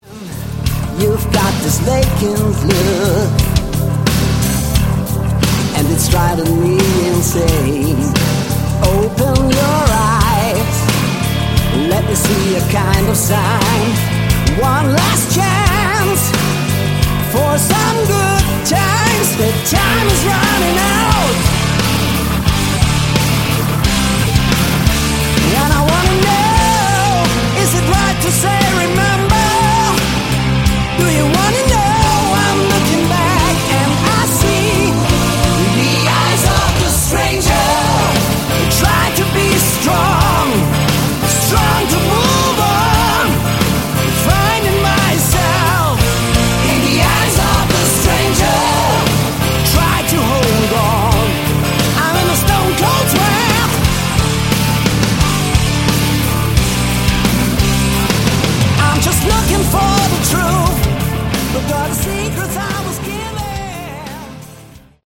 Category: Melodic Rock
Vocals
Keyboards
Sax
Guitars
Drums
Bass